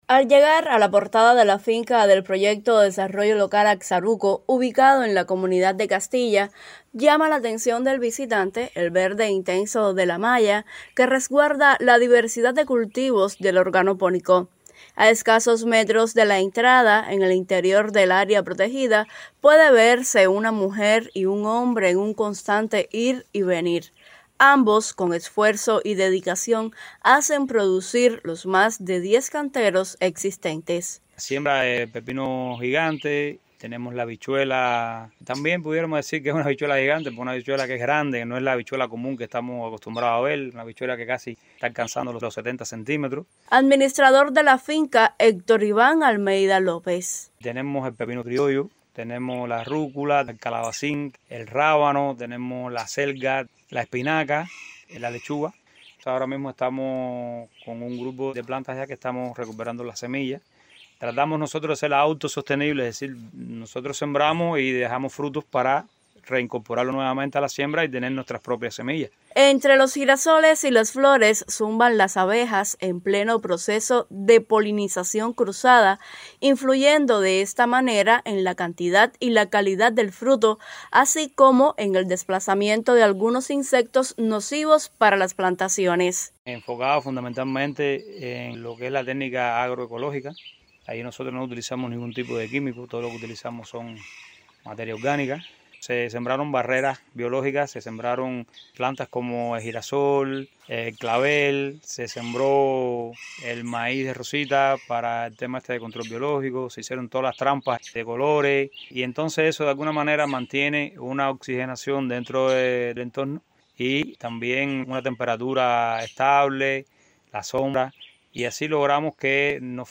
Detalles en la entrevista: